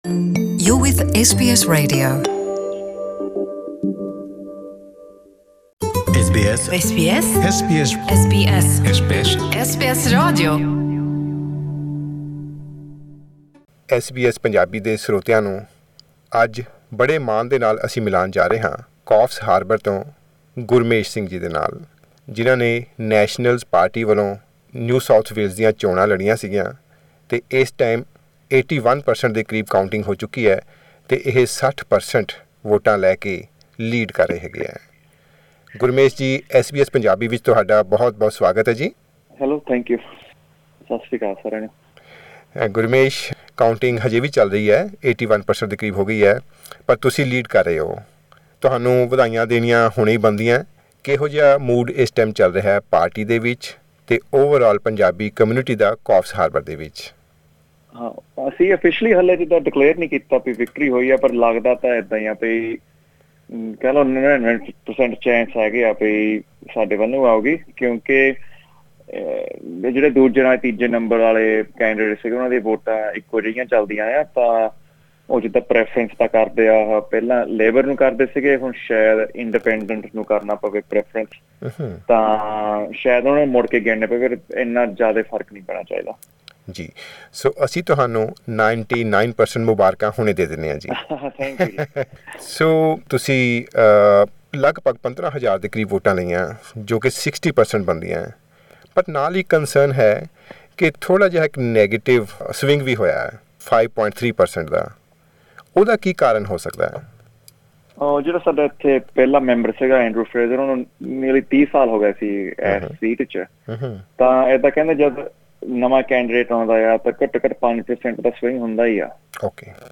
Mr Singh shared his passion for politics in an interview with SBS Punjabi saying, ‘Since the last four years, I have been actively involved in local matters and came to know about how policies are formed and implemented.